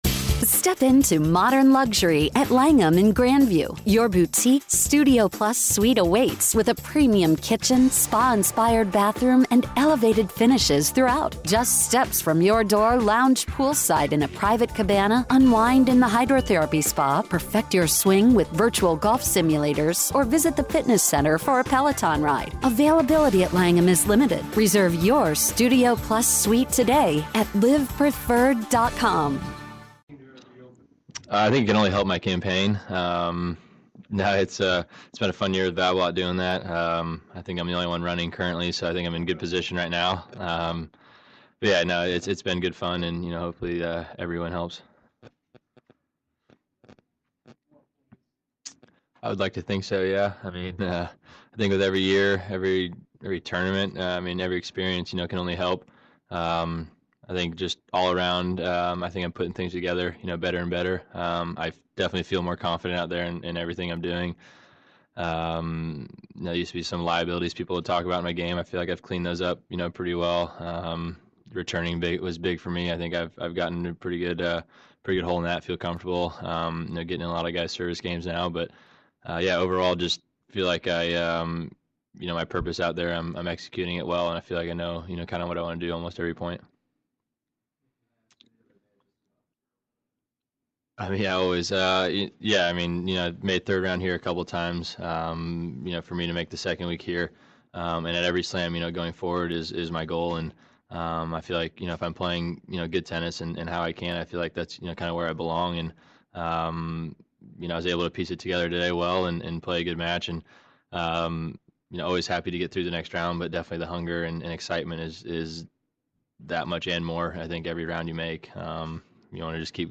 Jack Sock Press Confernece
Jack Sock speaks with media following his victory over Marin Cilic.